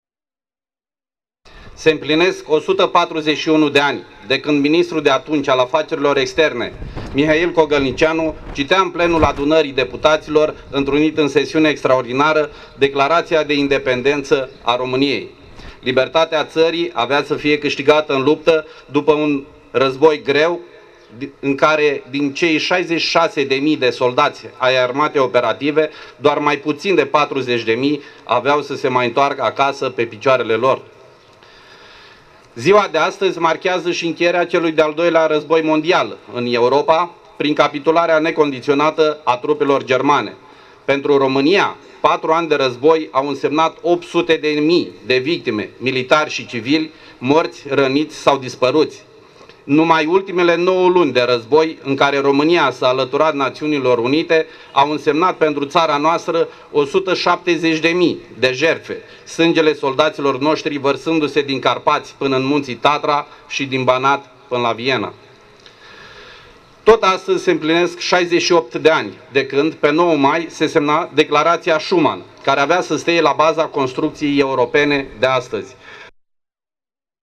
Piața Independenței.